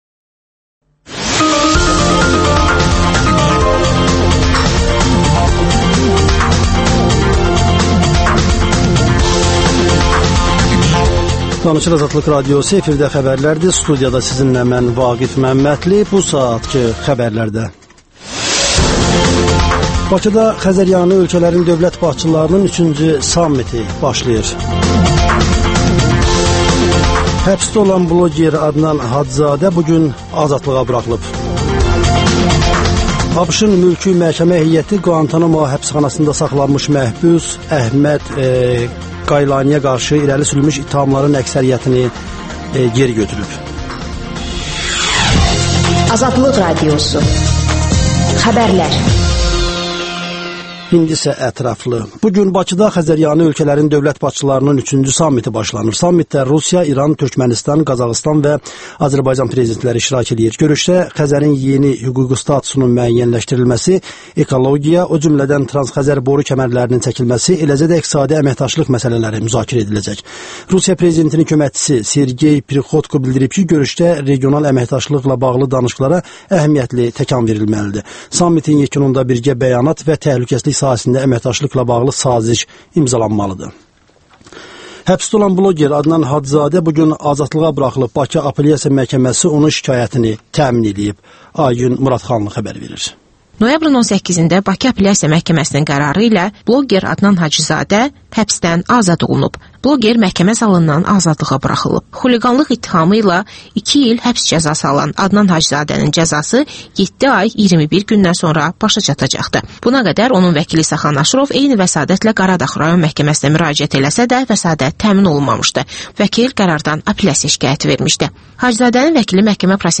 canlı efirdə